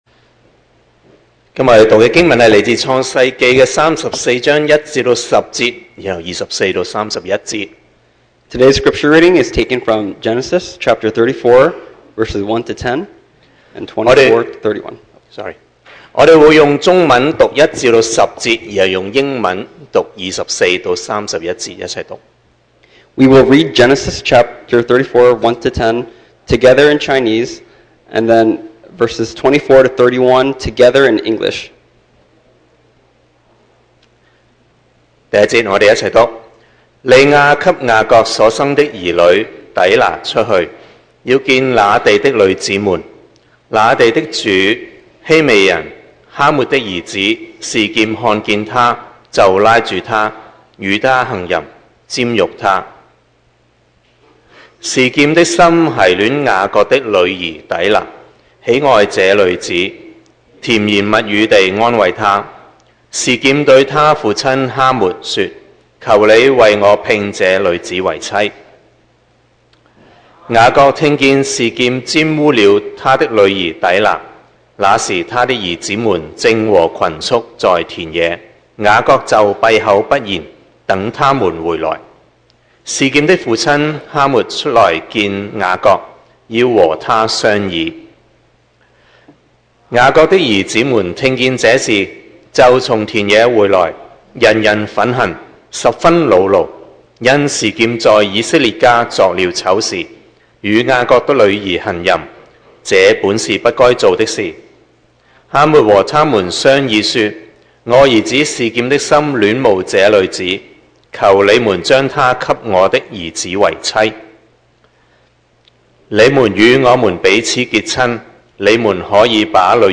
Passage: Genesis 34:1-10, Genesis 34:24-31 Service Type: Sunday Morning